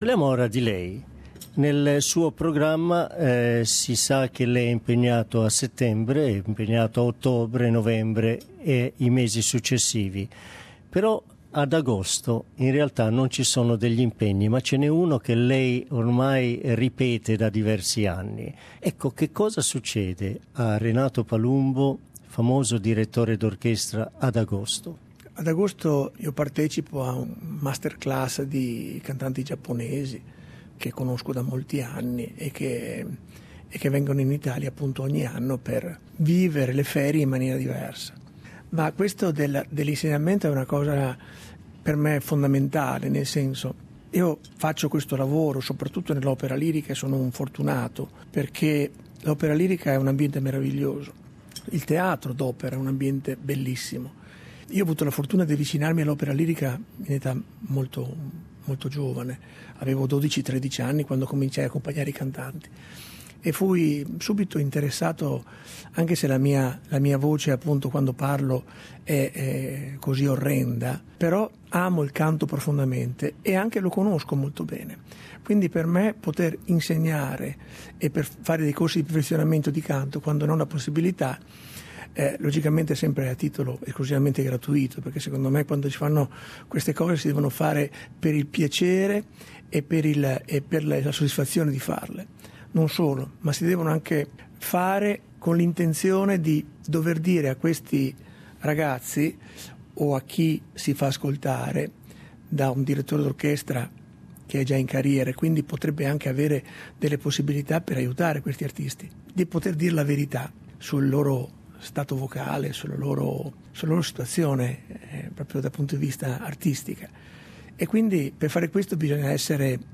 This week's classical music segment features our interview with Renato Palumbo, who is directing Giuseppe Verdi's Rigoletto at Sydney's Opera House.